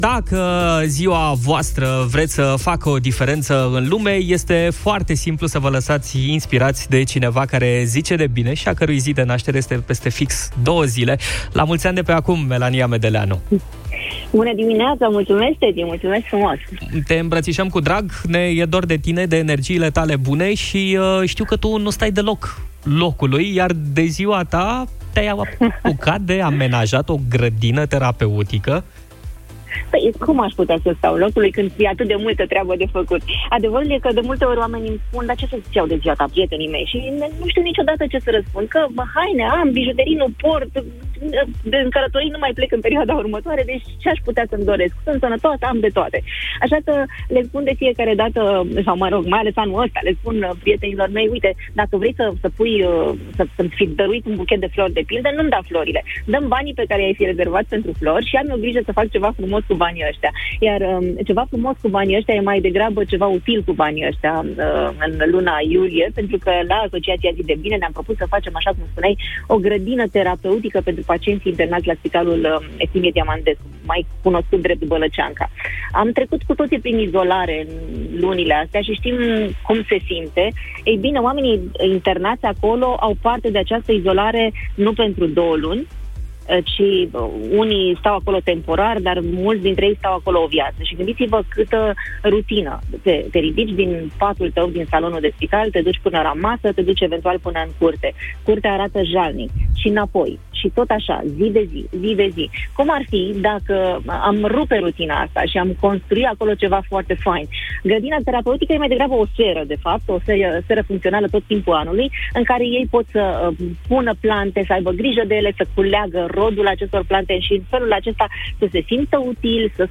De ziua ei, Melania Medeleanu face o Grădină terapeutică la Spitalul de Psihiatrie Eftimie Diamandescu, despre care a vorbit în direct la Europa FM, în Europa Express: